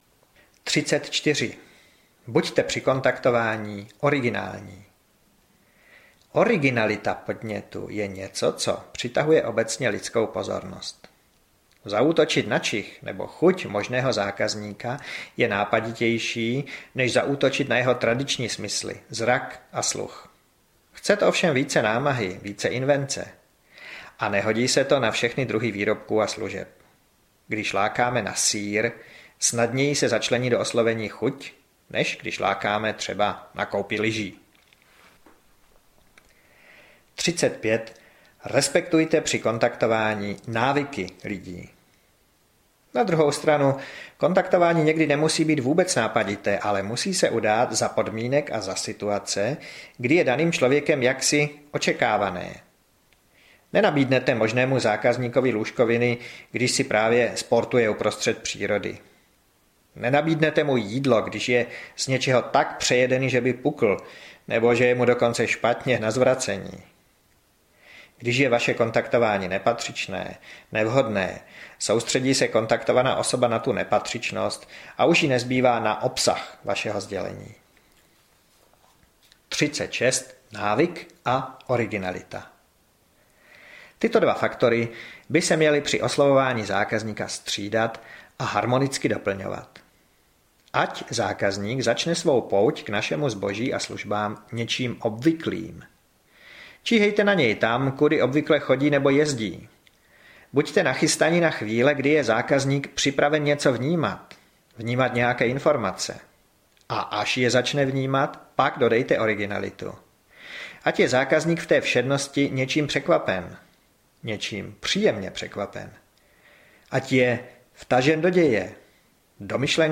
Pokud neprodáte, jako byste nebyli audiokniha
Ukázka z knihy